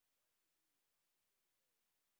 sp05_white_snr0.wav